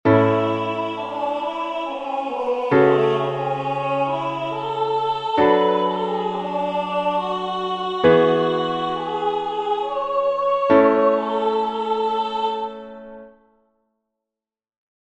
Fixádevos no seguinte fragmento; podedes observar que algunhas das notas da melodía non pertencen aos acordes que as acompañan.
Partitura voz e piano con acordes
No penúltimo compás, o acorde é o V grao de La M, polo que as notas son Mi, Sol, Si.